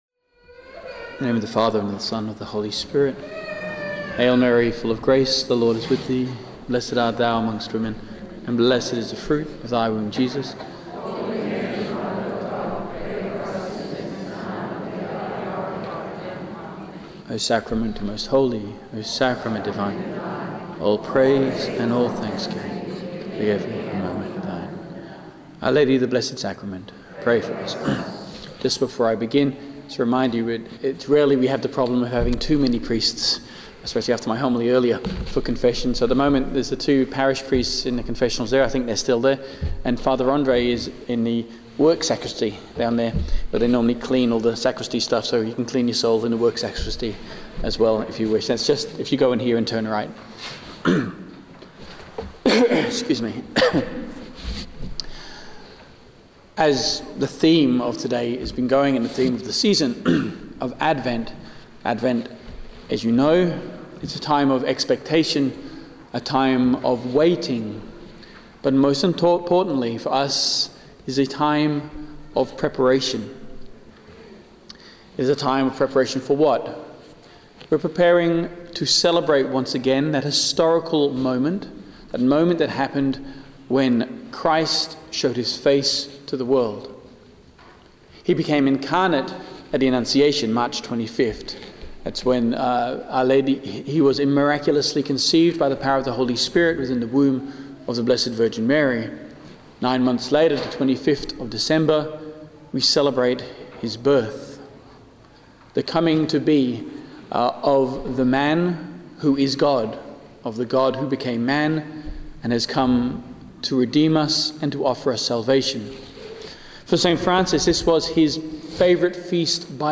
speaks on the Eucharist during the “Day With Mary” held at Our Lady of Lourdes Parish in Rockingham, Western Australia on 5 December 2015.